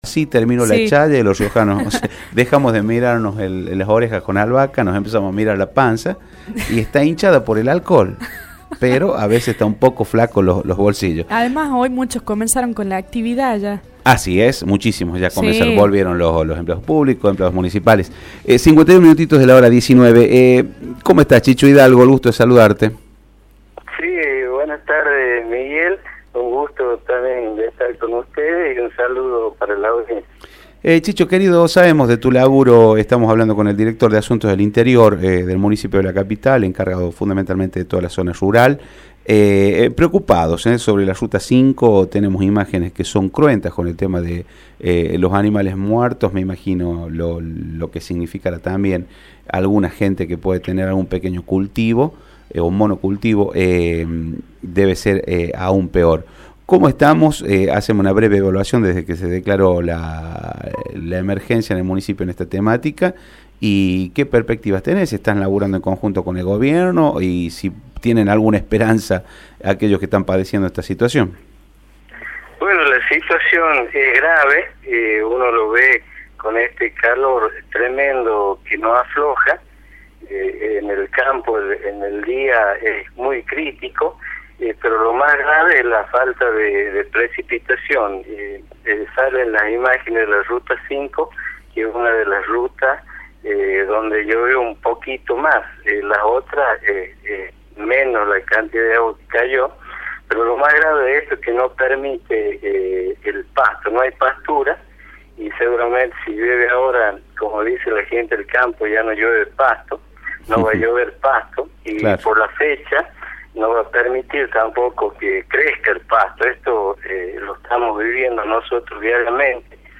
En declaraciones a Radio Rioja, Hidalgo dio una panorama aterrador en el interior del departamento Capital, a raíz de la falta de lluvias.